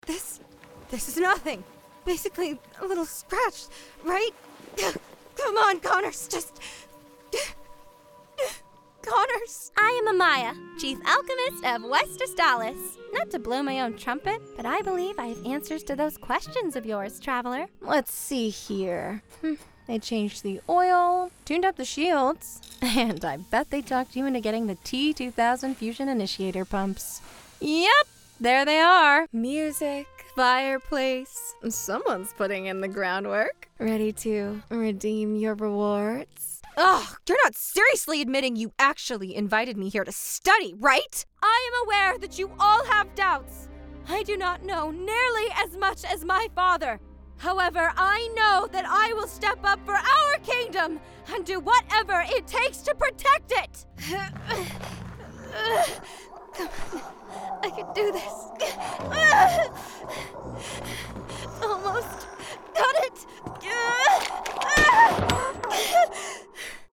Inglés (Americano)
Joven, Travieso, Versátil, Amable, Cálida